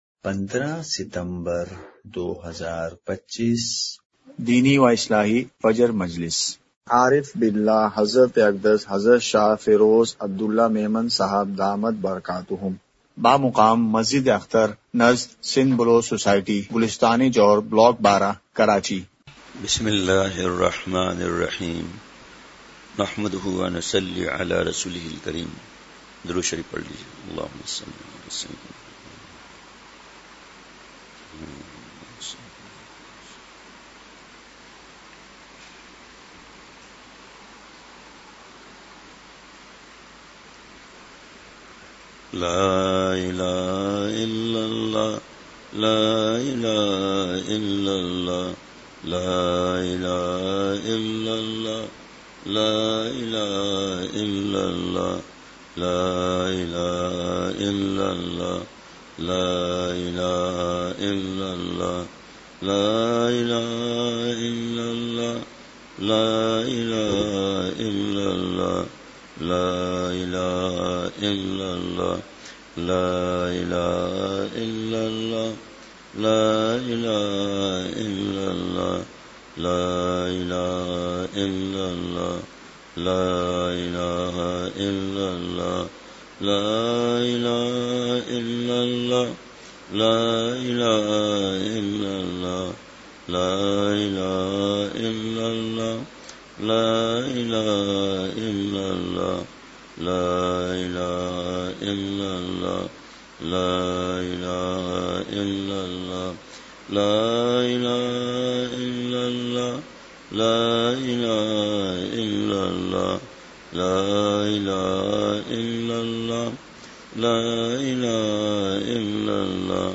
*مقام:مسجد اختر نزد سندھ بلوچ سوسائٹی گلستانِ جوہر کراچی*
05:56) مجلسِ ذکر:کلمہ طیّبہ کی ایک تسبیح۔۔۔!!